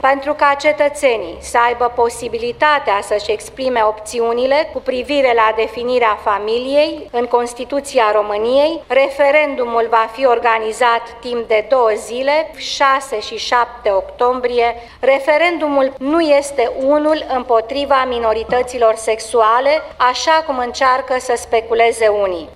Premierul Viorica Dăncilă spune că referendumul este efectul unui demers cetăţenesc:
Viorica-Dancila.wav